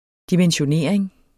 Udtale [ dimənɕoˈneɐ̯ˀeŋ ]